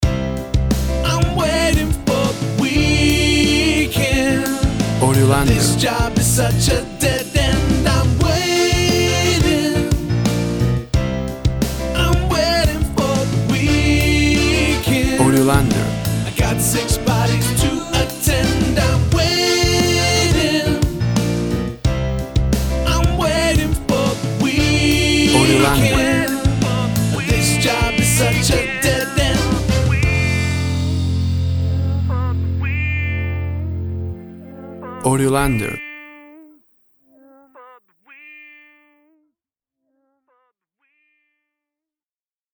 Tempo (BPM) 90